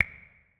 Babushka / audio / sfx / UI / Dialog / SFX_Dialog_05.wav
SFX_Dialog_05.wav